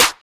[ACD] - TrapMusic Snare (1).wav